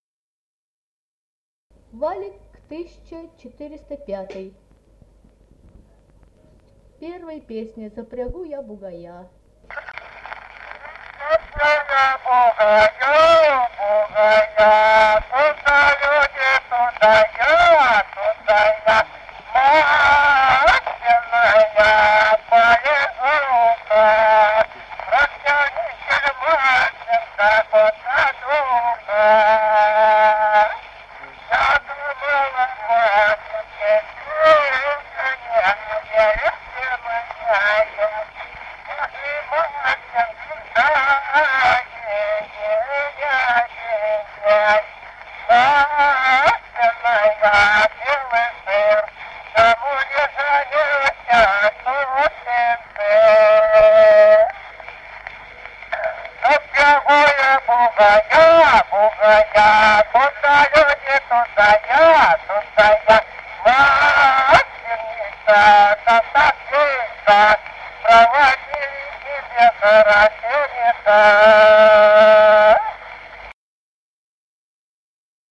Народные песни Стародубского района «Запрягу я бугая», масленичная.
д. Камень.